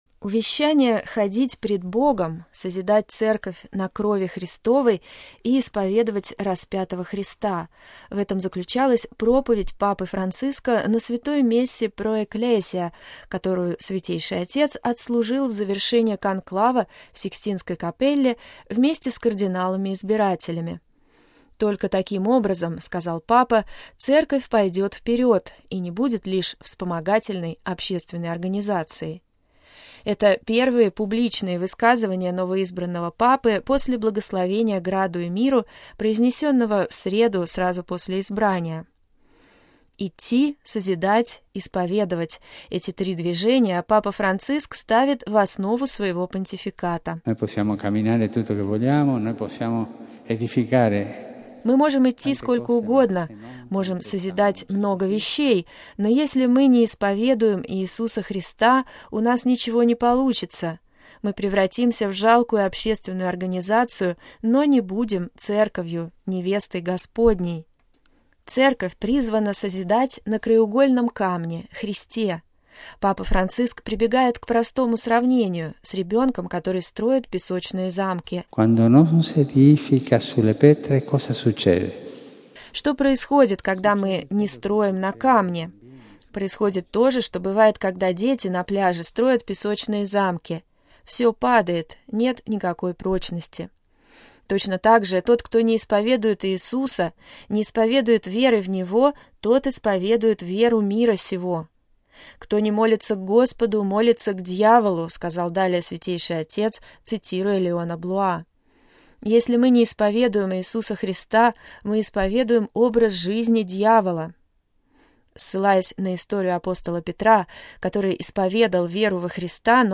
Спонтанная проповедь Папы Франциска – без помощи «шпаргалки» – завершилась просьбой быть безукоризненными, «ходить пред Богом», как Бог увещал Авраама.